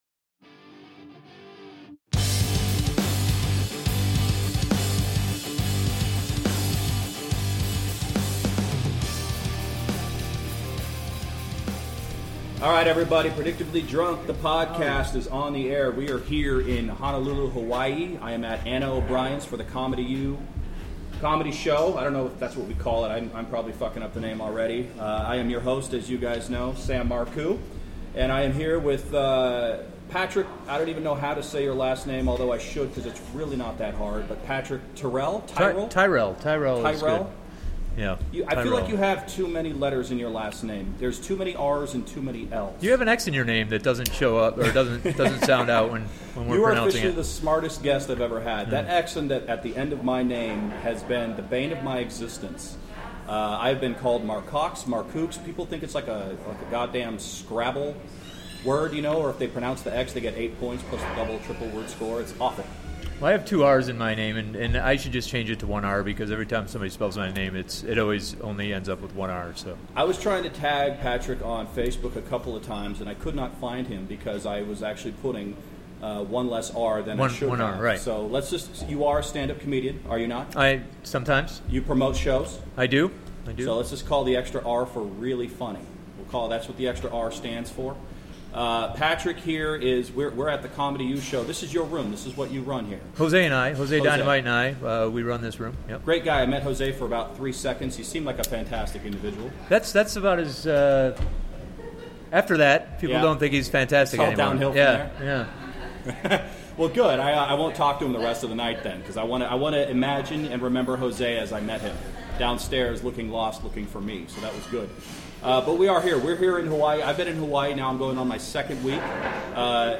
A LIVE recording on stage at The Comedy U Show at Anna O'Brien's in Honolulu, Hawaii with special guest